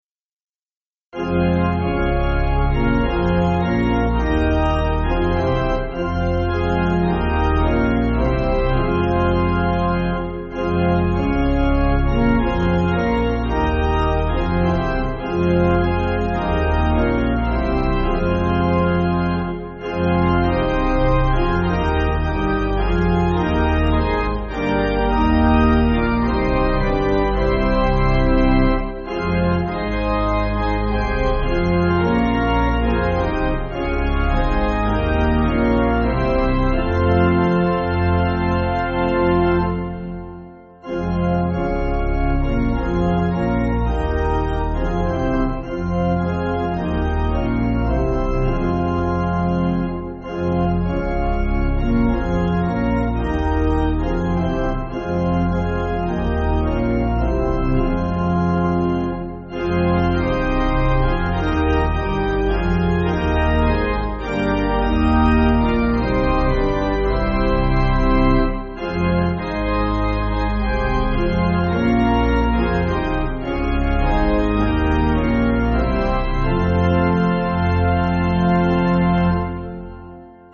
Organ
(CM)   4/Fm